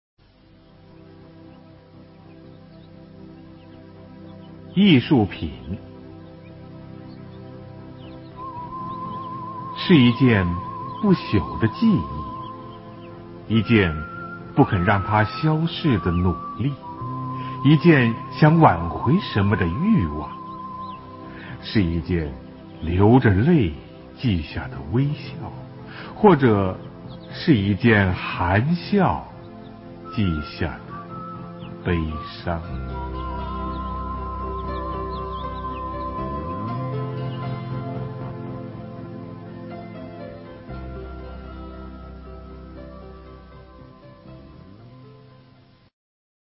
艺术品 席慕容 经典朗诵欣赏席慕容：委婉、含蓄、文雅 语文PLUS